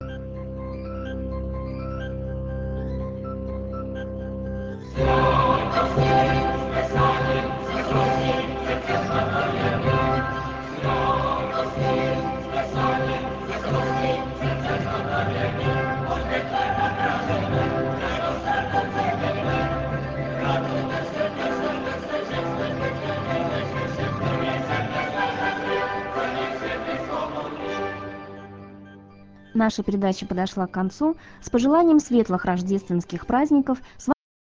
И в завершении мессы звучит музыка, олицетворяющая радость приходу Спасителя и всеобщее веселье.